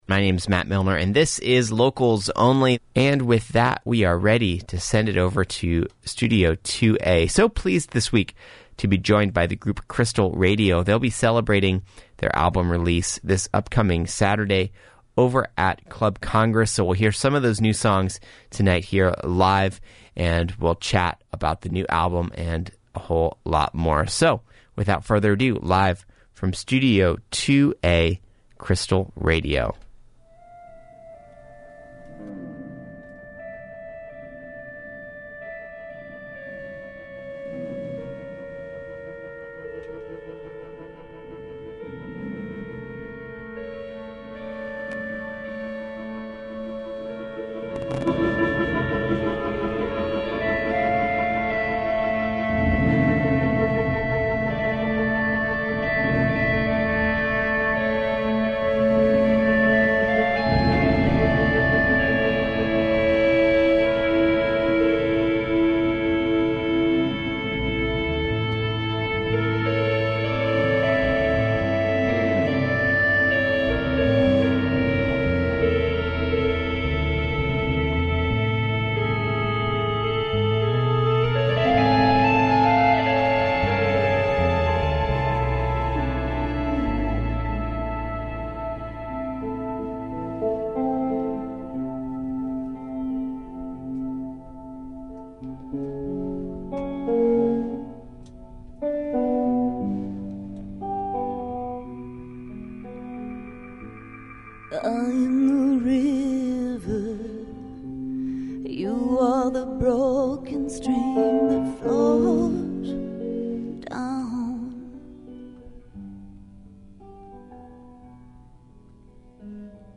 In between sets of live music